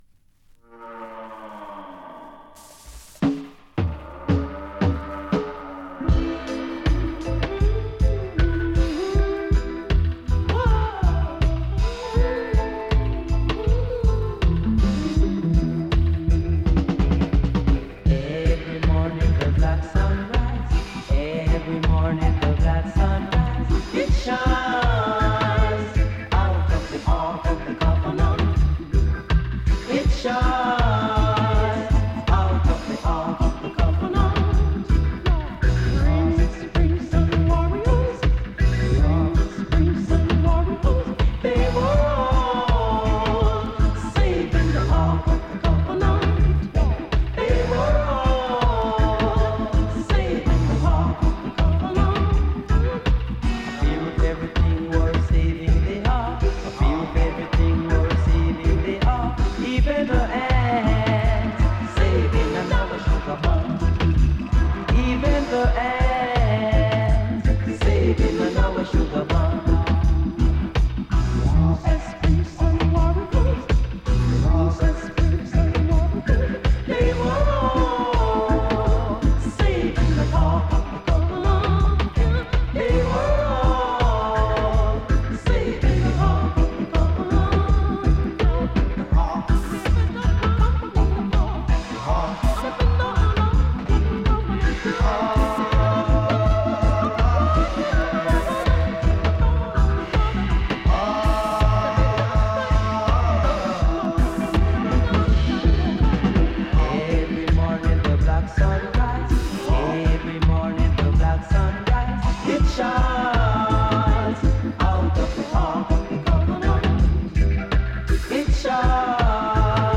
100% vinyles Reggae Roots